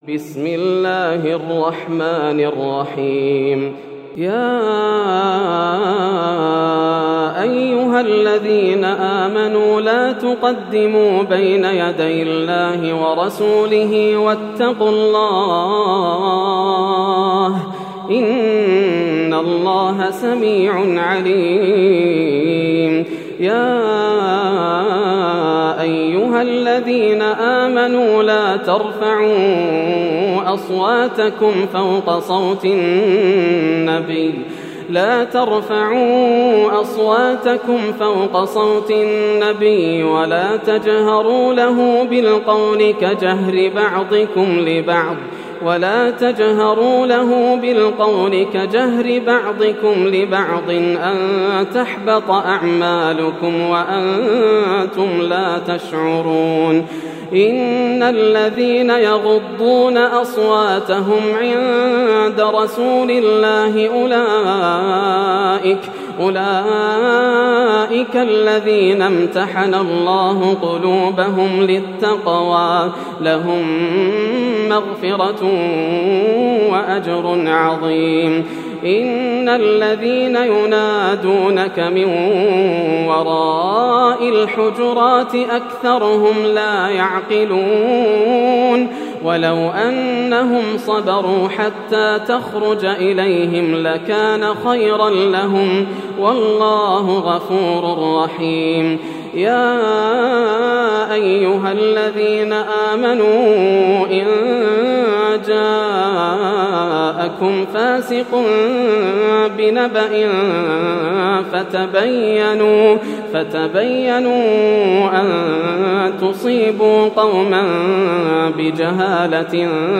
سورة الحجرات > السور المكتملة > رمضان 1431هـ > التراويح - تلاوات ياسر الدوسري